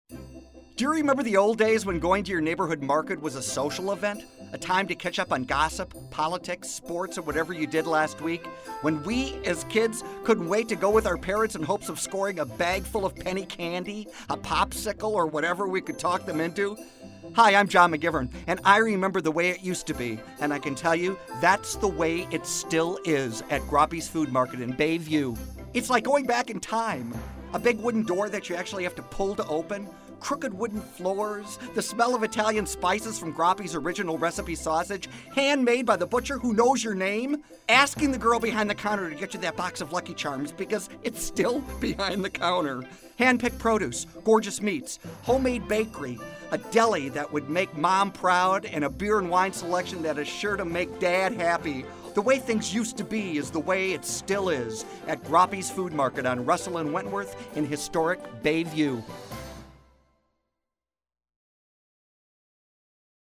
Groppi’s Food Market Radio Commercial